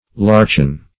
Larchen \Larch"en\ (l[aum]rch"[e^]n), a. Of or pertaining to the larch.